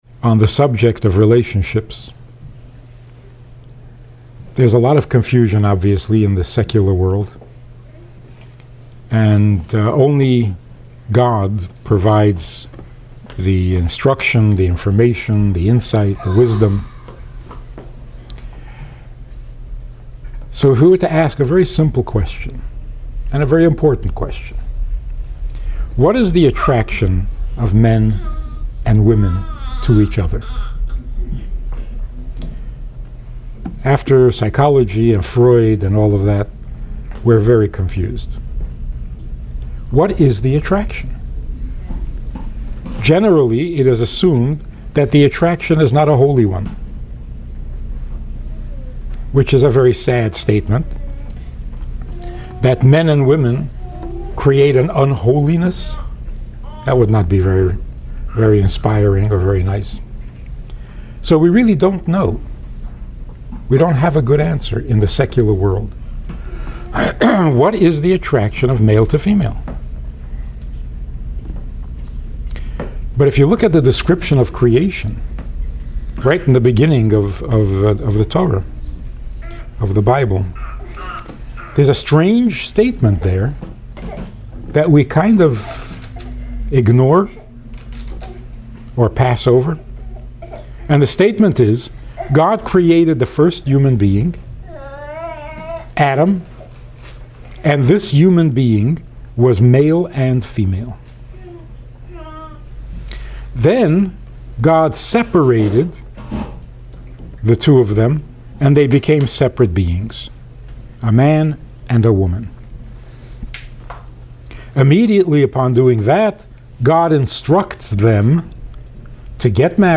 In 2012, a group of Mennonites visited Crown Heights, Brooklyn to learn about the Hasidic philosophy.